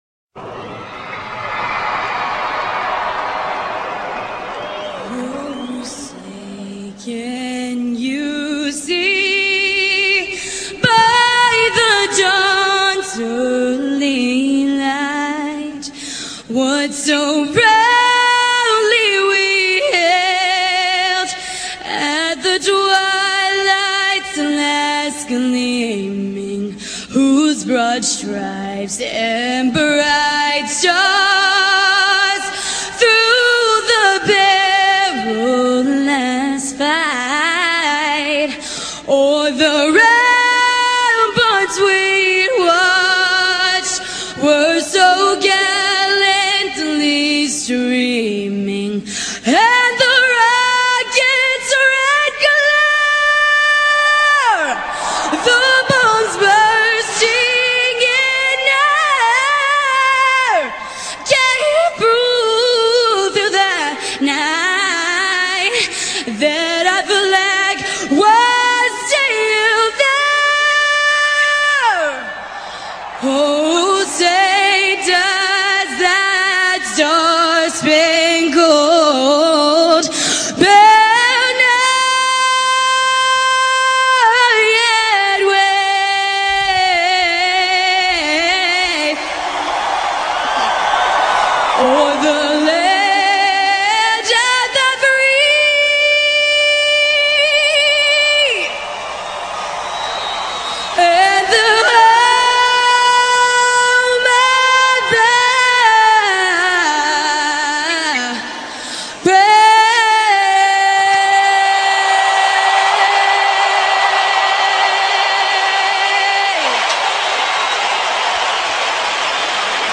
Demi Lovato Cantando el himno Nacional04.mp3